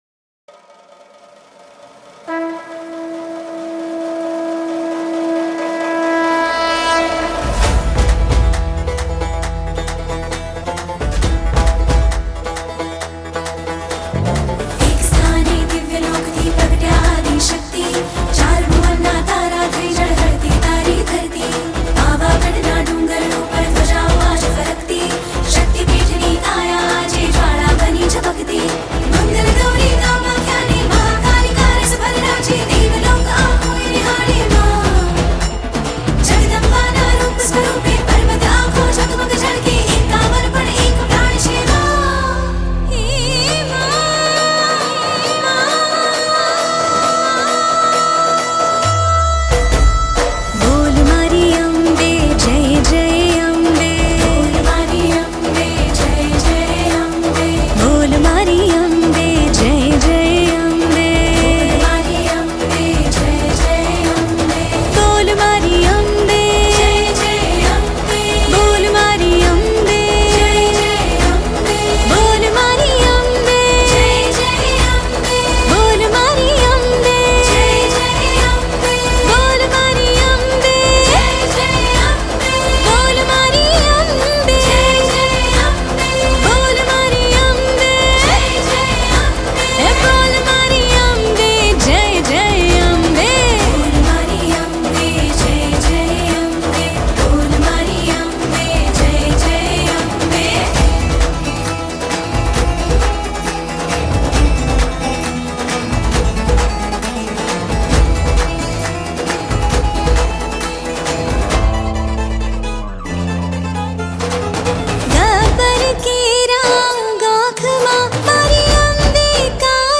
Gujarati Bhakti Song
Navratri Special Song